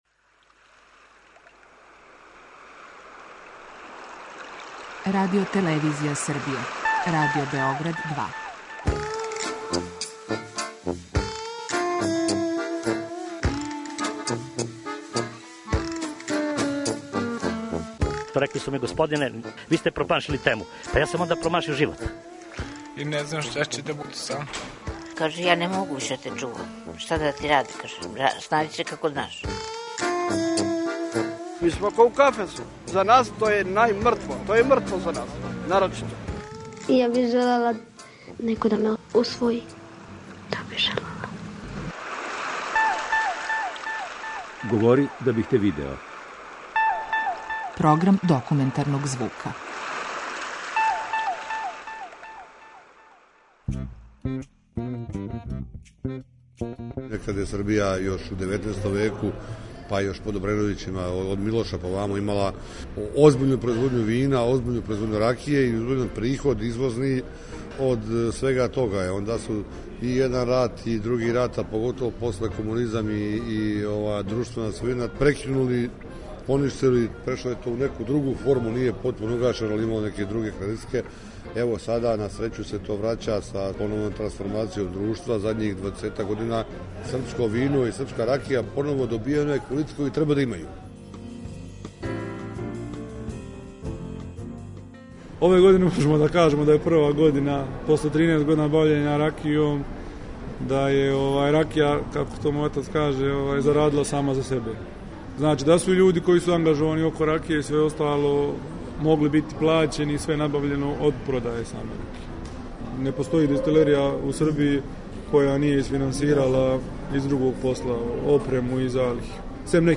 Документарни програм: репортажа „Земља добре ракије”
Ова оригинална продукција Радио Београда 2 сједињује квалитете актуелног друштвеног ангажмана и култивисане радиофонске обраде.